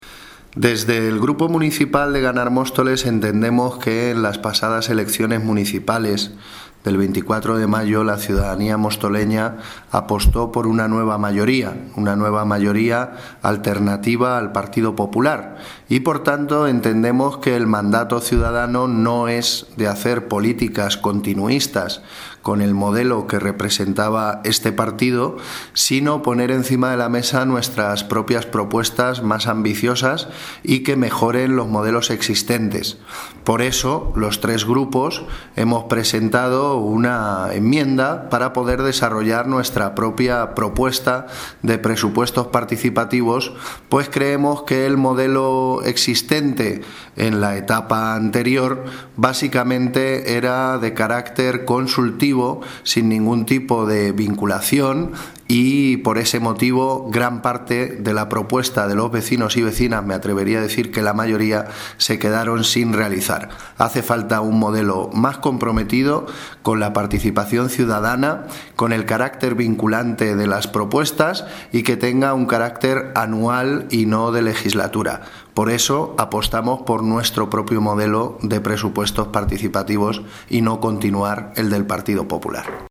Audio - Gabriel Ortega (Portavoz de Ganar Móstoles) Sobre Presupuestos Participativos